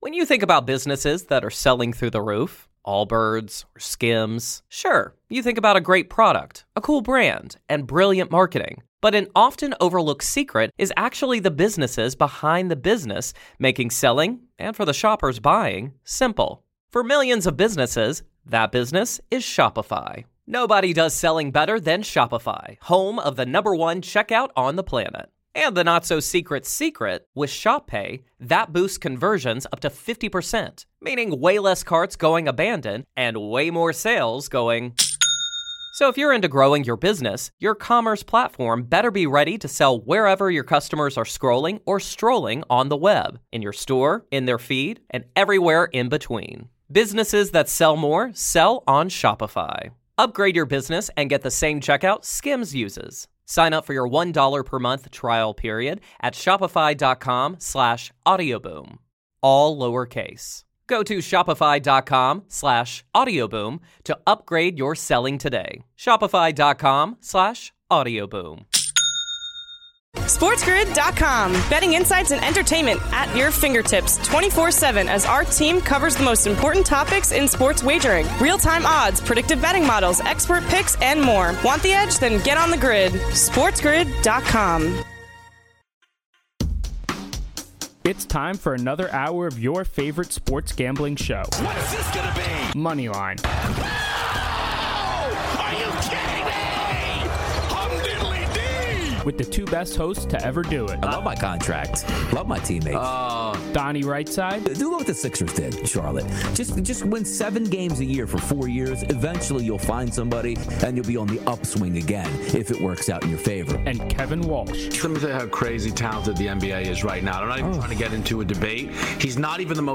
Then, he gets into all the College basketball games tonight. He gives you all his best bets from across the landscape, takes your calls, and more!